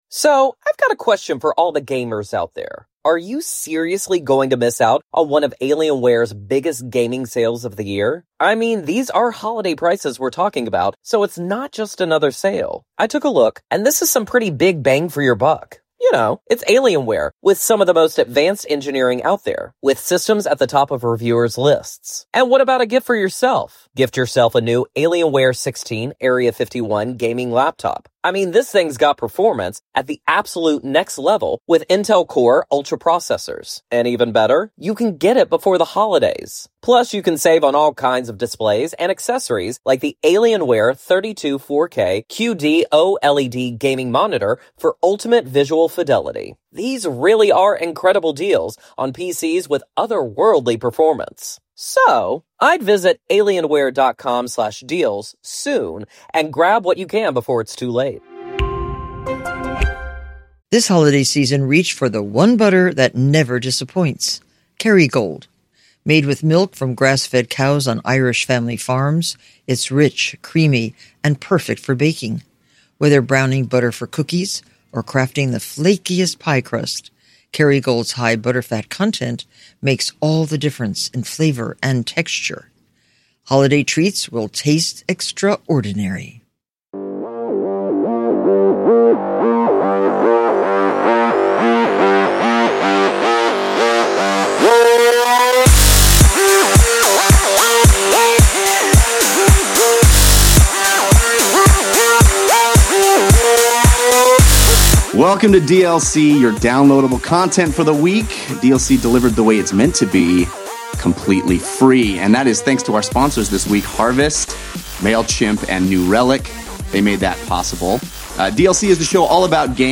All that, plus tons of live call in and a surprise guest!